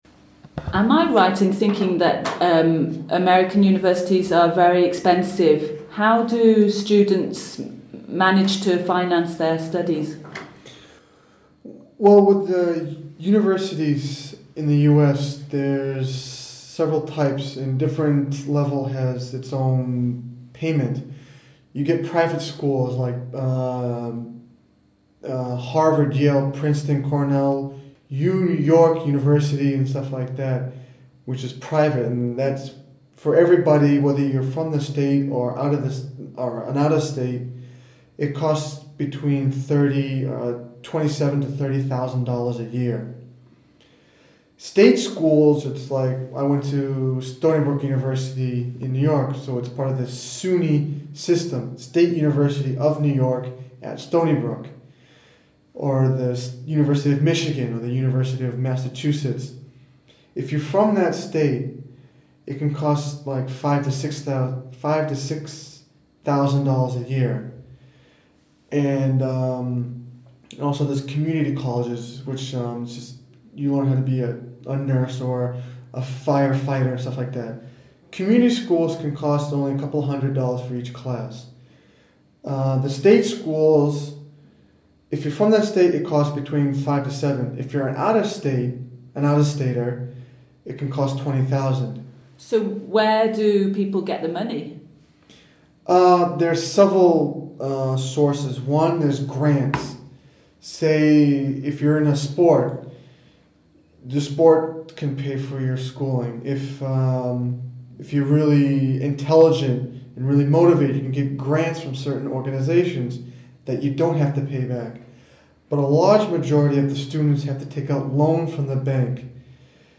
Britannique / Américain